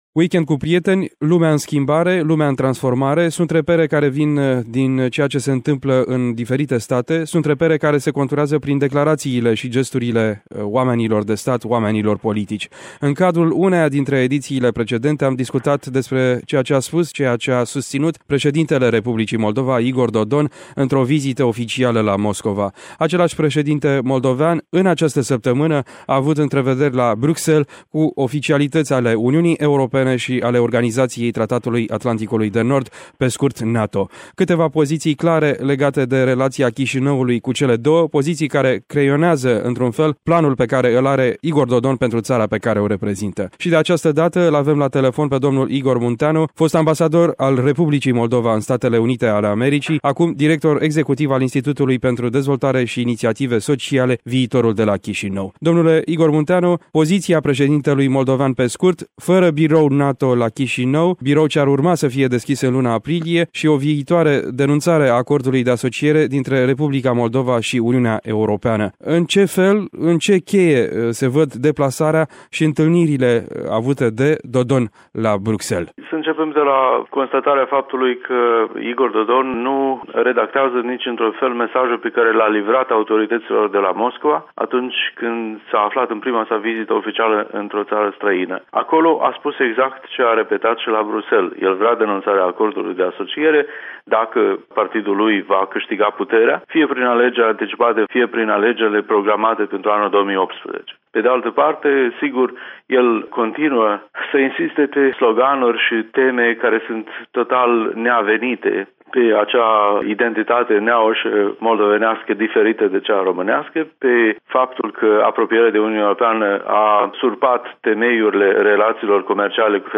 Pe subiectul vizitei și al pozițiilor transmise de către președintele moldovean, a oferit câteva interpretări Igor Munteanu, fost ambasador al Republicii Moldova la Washington, la ora actuală, director executiv al Institutului pentru Dezvoltare și Inițiative Sociale ”Viitorul” de la Chișinău.